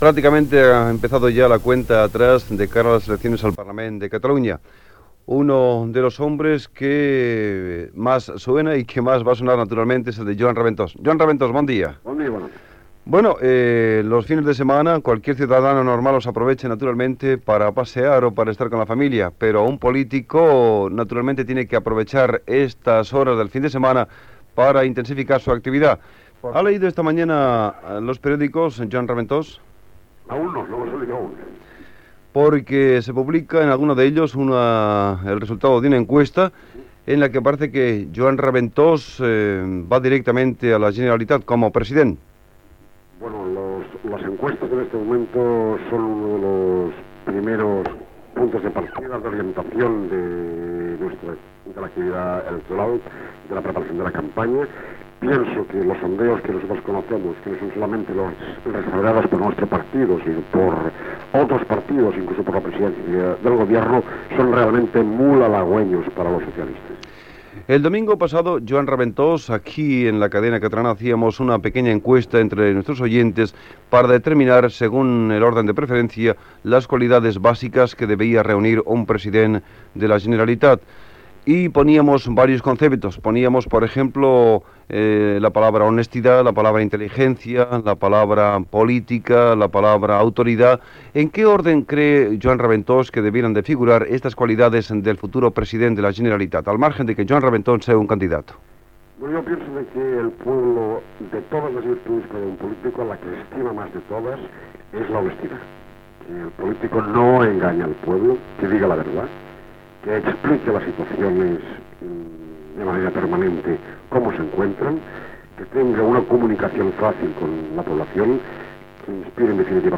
Entrevista al polític Joan Raventós, candidat a la presidència de la Generalitat pel Partit Socialista de Catalunya